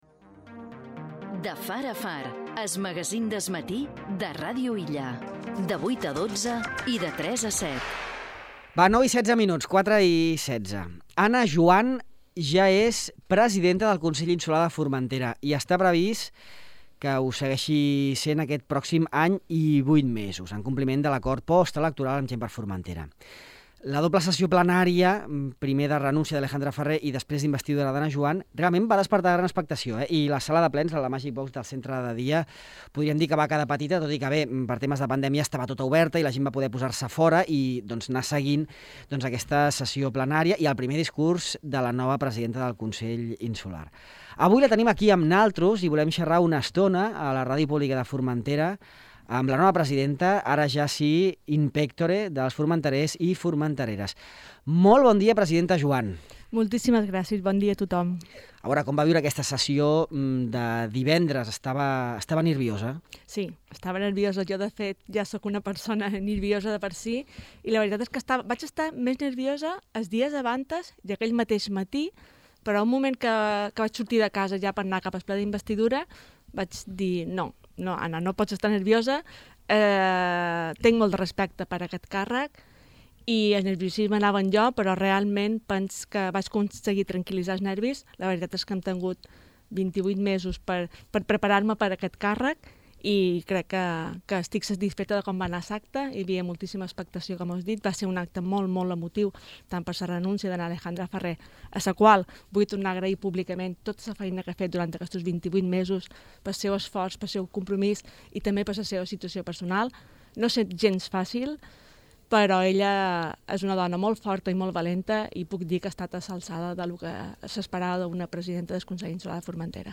La nova presidenta del Consell de Formentera, Ana Juan, explica a Ràdio Illa quines seran les bases del seu mandat, que si no hi ha sorpreses, exercirà el pròxim any i 8 mesos, fins a final de legislatura. La cap de l’executiu insular ha afirmat que millorar l’accés a l’habitatge és una de les seves prioritats.